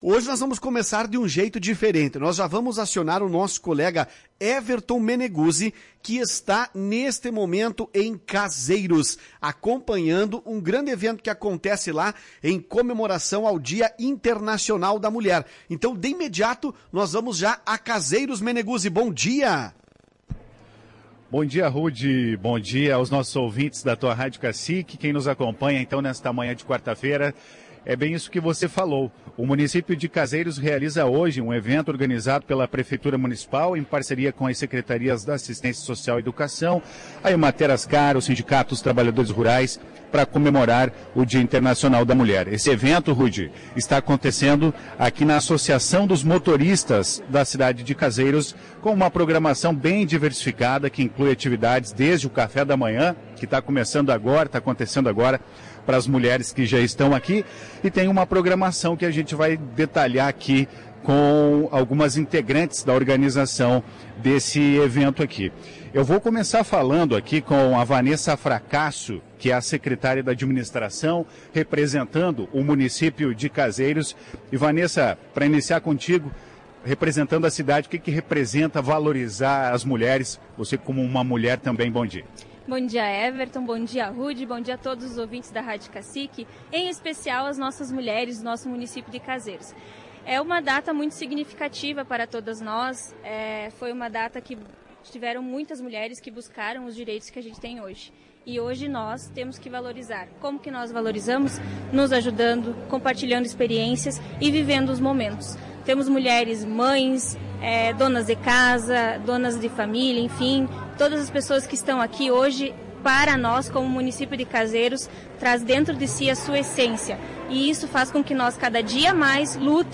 A reportagem da Tua Rádio Cacique conversou com a secretária da Administração, Vanessa Fracasso, que frizou celebrar as conquistas das mulheres.